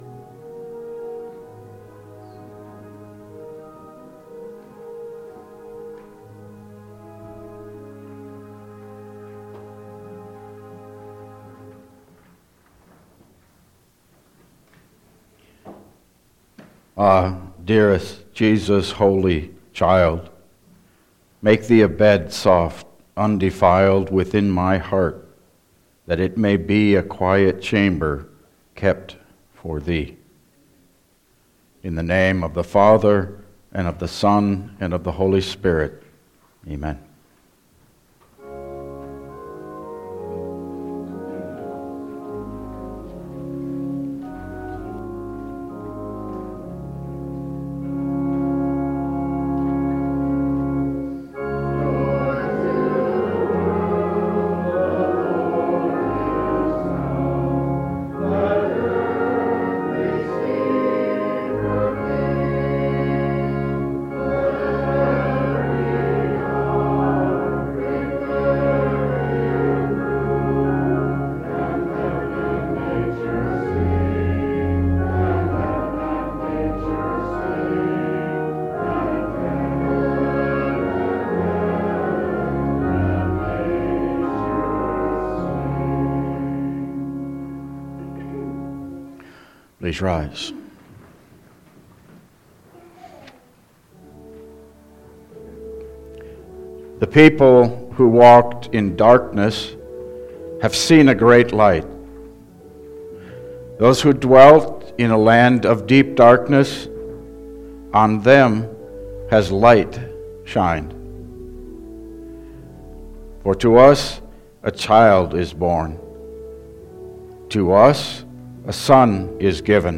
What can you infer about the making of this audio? Service Type: Christmas Day